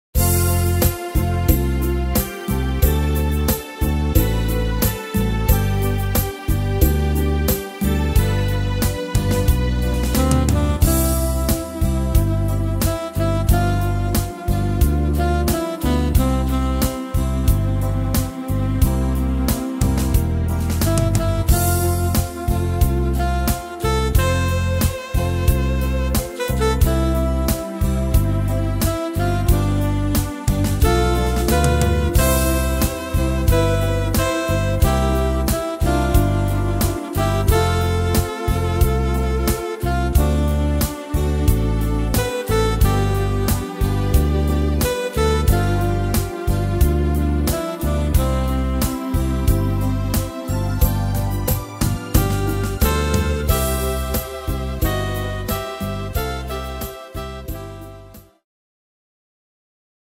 Tempo: 90 / Tonart: C-Dur